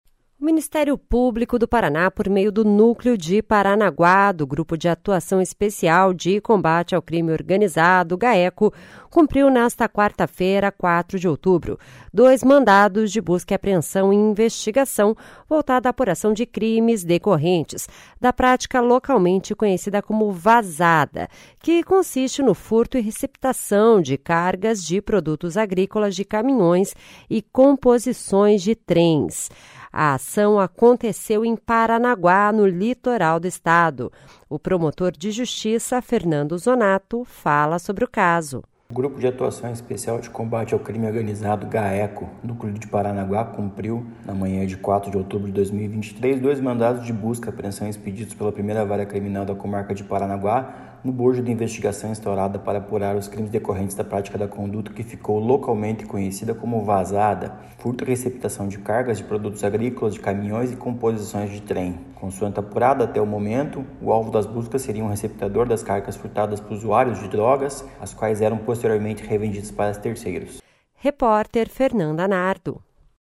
O promotor de Justiça, Fernando Zonatto, fala sobre o caso.